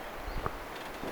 viy-tiltaltin ääni